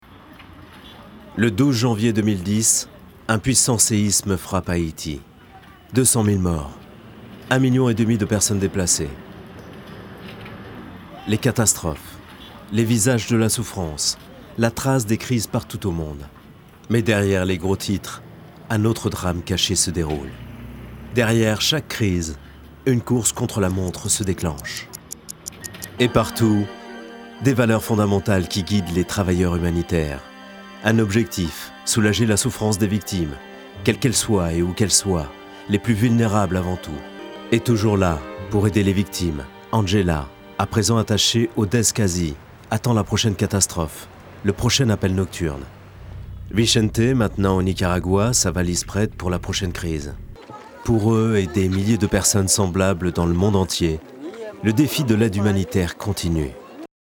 Male
Assured, Character, Confident, Conversational, Cool, Corporate, Deep, Engaging, Friendly, Gravitas, Natural, Reassuring, Sarcastic, Smooth, Soft, Versatile, Warm
corporate.mp3
Microphone: Neumann TLM103